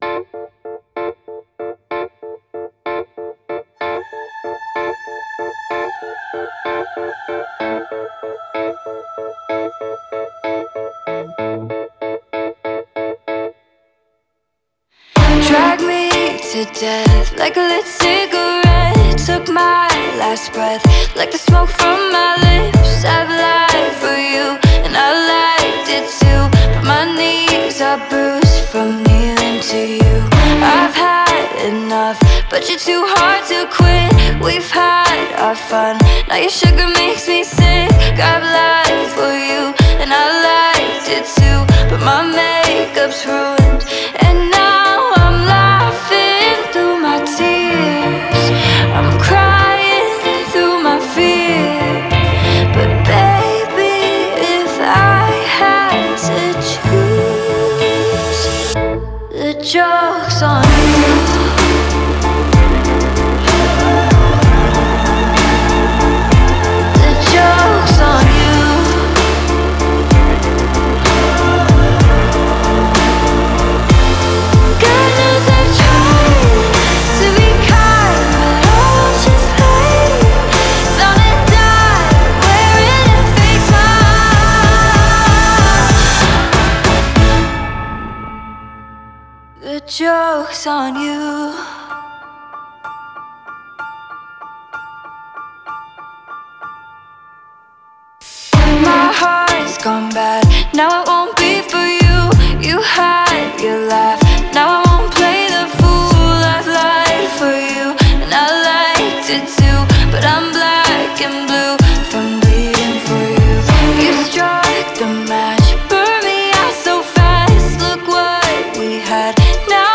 • Жанр: Зарубежные песни